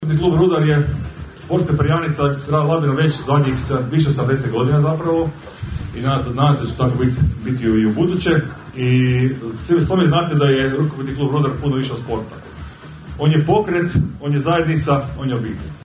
Pod nazivom "Season Kick-off 2025./26.", Rukometni klub Rudar jučer je u prelijepom ambijentu Glamping campa Floria predstavio viziju, ciljeve, momčad, stručni stožer i novi Izvršni odbor za nadolazeću sezonu.